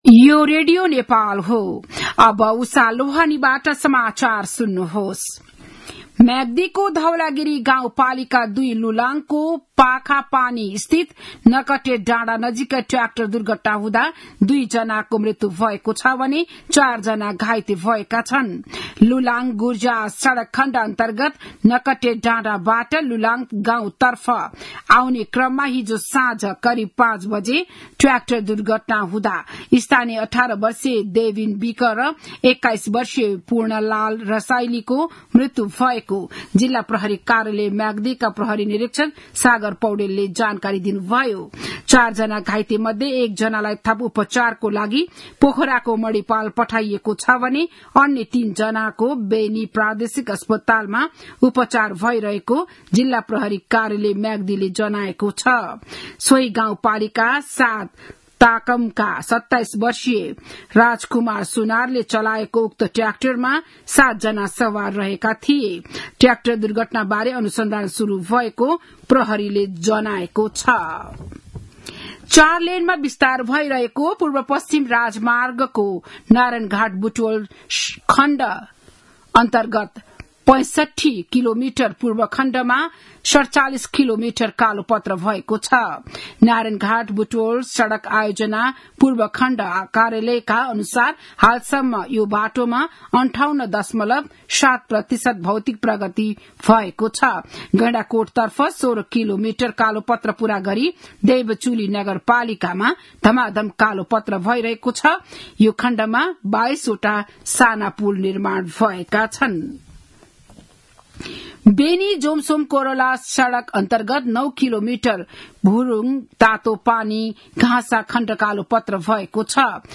बिहान ११ बजेको नेपाली समाचार : ८ चैत , २०८१
11-am-news-1-6.mp3